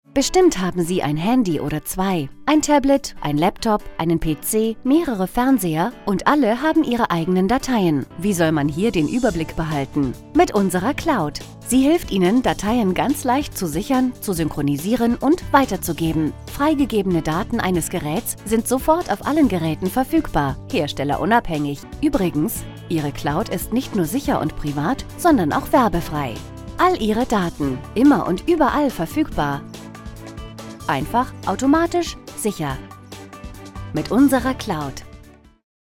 Erfahrene Sprecherin für deutsche und internationale Produktionen in zwei Muttersprachen: Deutsch und Englisch.
Sprechprobe: Werbung (Muttersprache):
Warm, sincere, young, believable, sexy, serious, elegant and vivacious - whatever you desire!